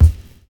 VINYL 14 BD.wav